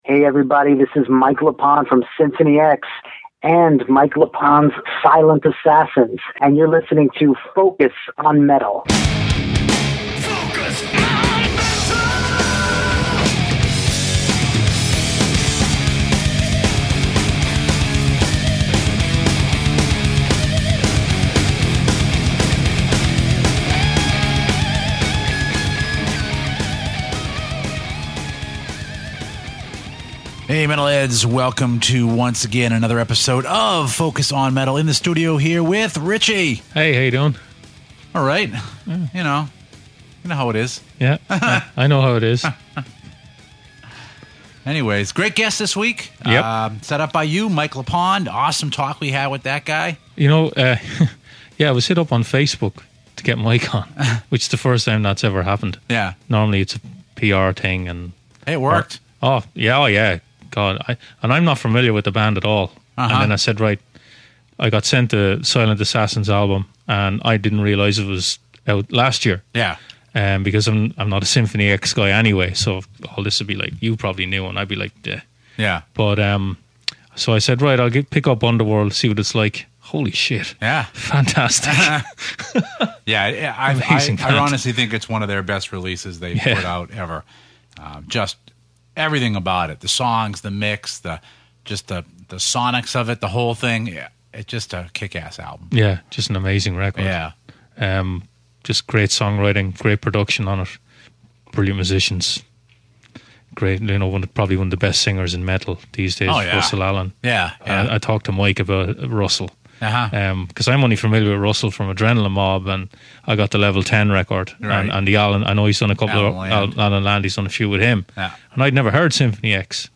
Also this week a bit of discussion on some timely metals topics and of course a track of the week.